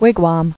[wig·wam]